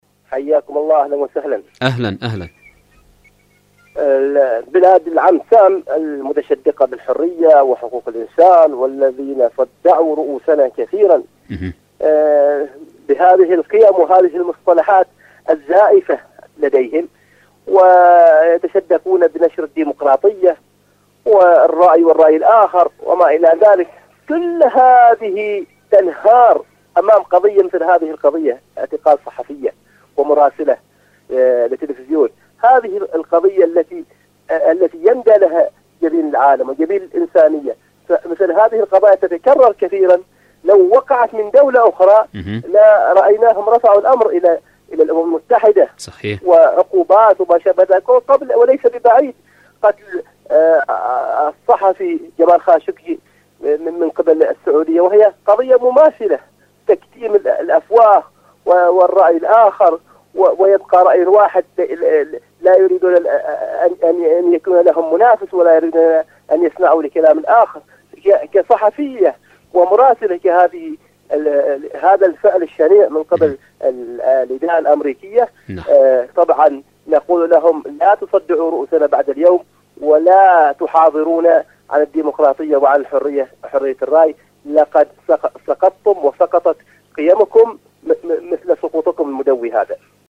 ألو طهران / مشاركة هاتفية